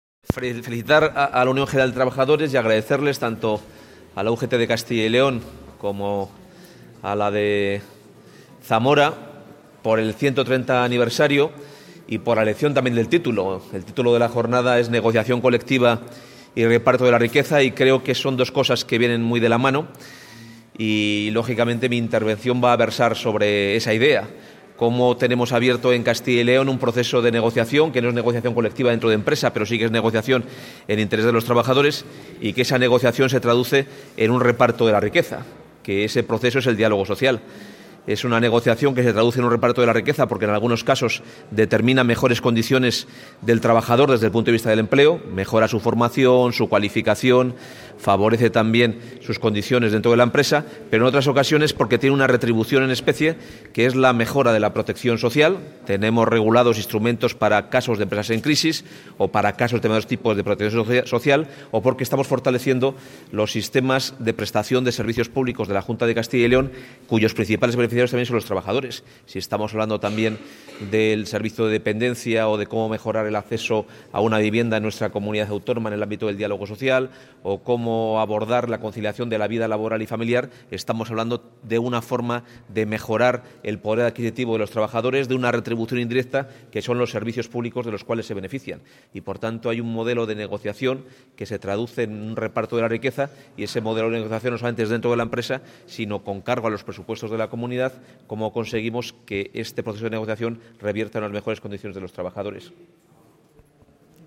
Declaraciones del consejero de Empleo en la jornada 'Negociación Colectiva y Reparto de la Riqueza'
Declaraciones del consejero de Empleo en la jornada 'Negociación Colectiva y Reparto de la Riqueza' Contactar Escuchar 8 de noviembre de 2018 Castilla y León | El consejero de Empleo, Carlos Fdez. Carriedo, ha participado hoy en Zamora en la mesa redonda sobre el papel de los sindicatos en la sociedad actual, en la jornada 'Negociación Colectiva y Reparto de la Riqueza', organizada por UGT.